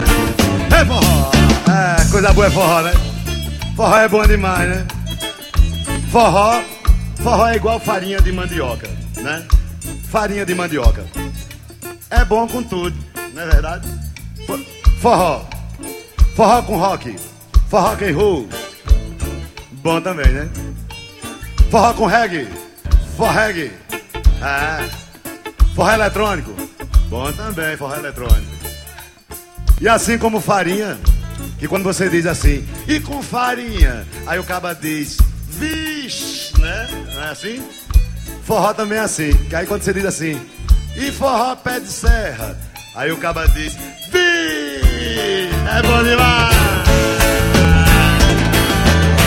FORRÓ ALTERNATIVO.